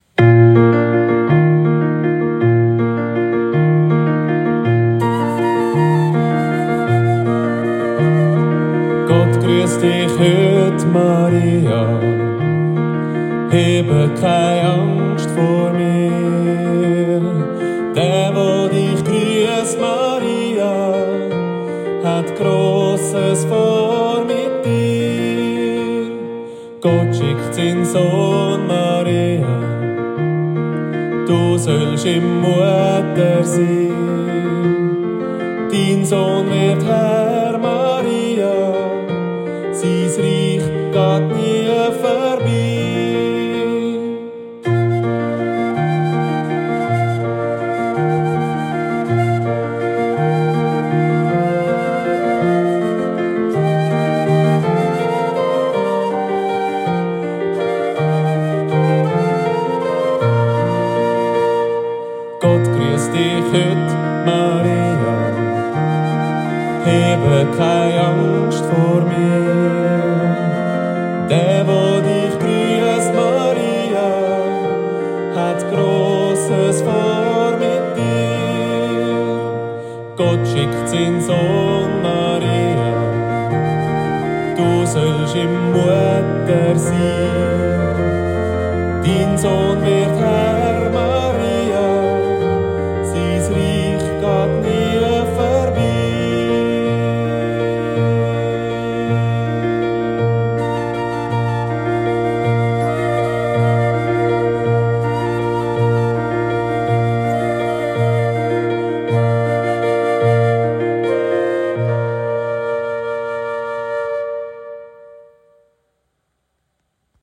Lieder zum Üben für den Kinderchor